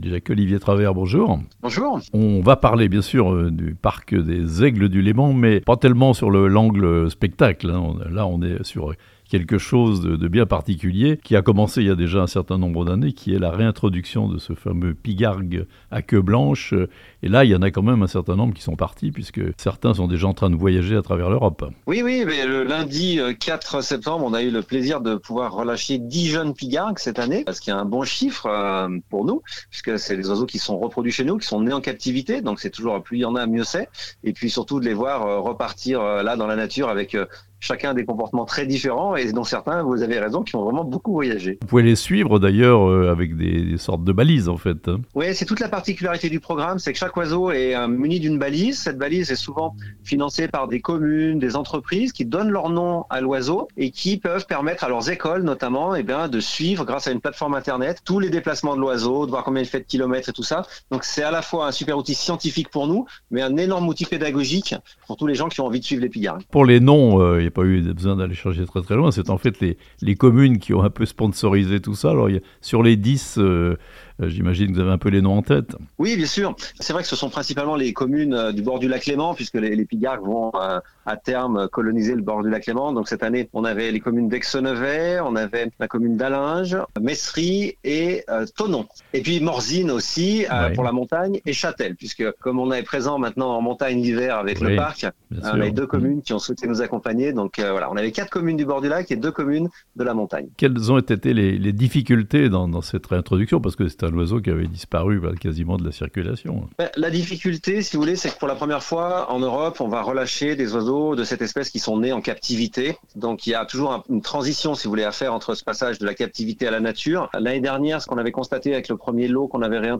85 aigles "pygargues à queue blanche" s'envoleront de Sciez d'ici à 2030 pour retrouver librement la nature (interview)